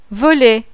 VOLET.wav